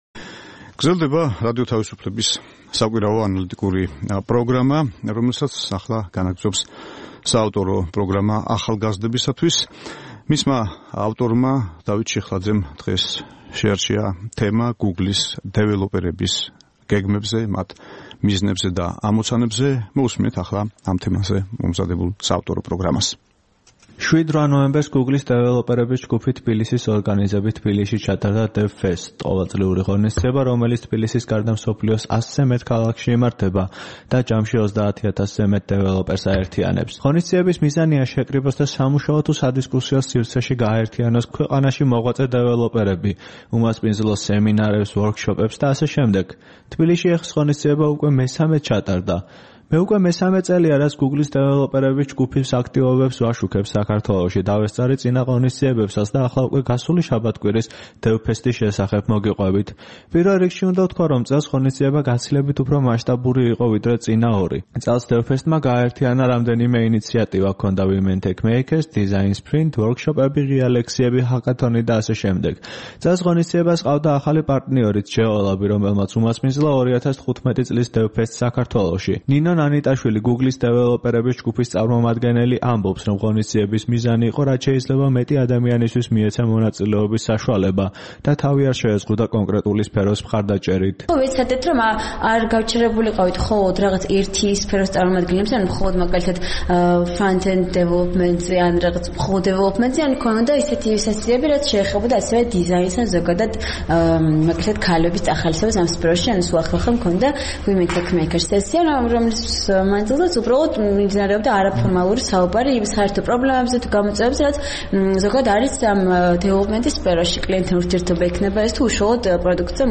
ჰაკათონის მონაწილეებს და მოწვეულ სპეციალისტებს გუგლიდან. სიუჟეტში მოისმენთ ჩანაწერს დისკუსიიდან "Women TechMakers" და გაიგებთ, თუ საქართველოს რომელ ქალაქებში გეგმავს გუგლის დეველოპერების ჯგუფი განვითარებას.